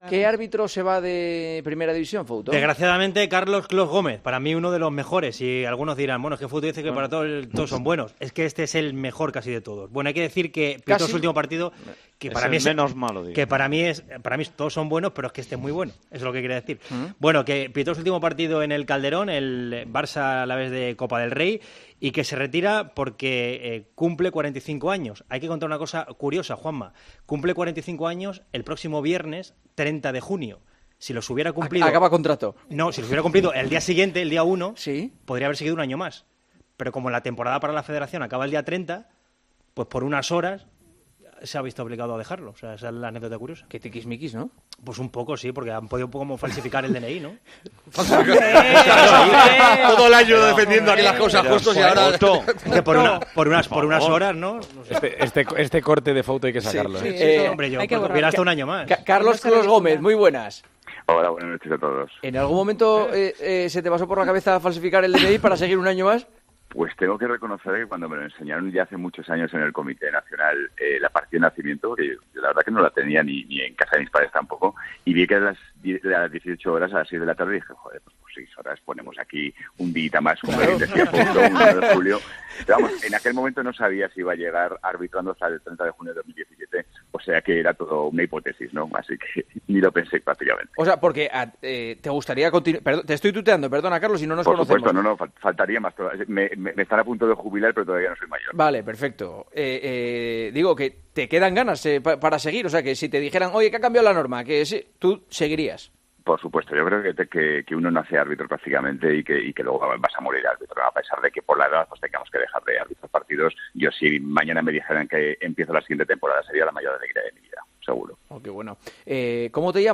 Hablamos en El Partidazo de COPE con el árbitro aragonés que se acaba de retirar del fútbol.